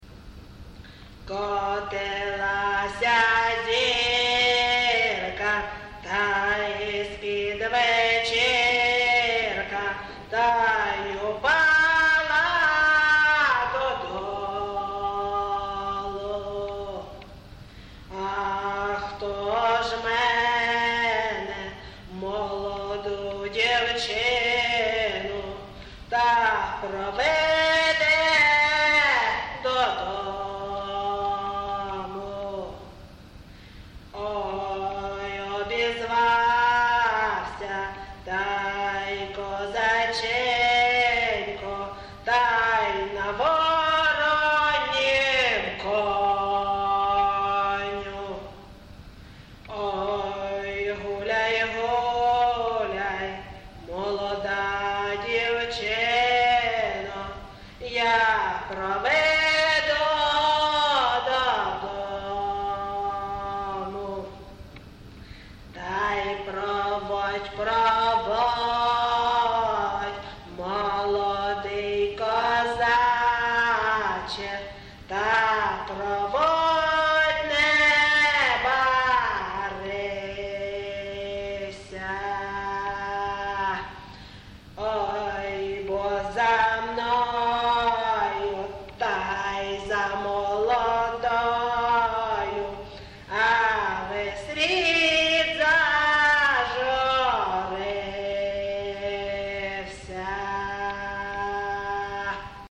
ЖанрПісні з особистого та родинного життя
Місце записус. Ковалівка, Миргородський район, Полтавська обл., Україна, Полтавщина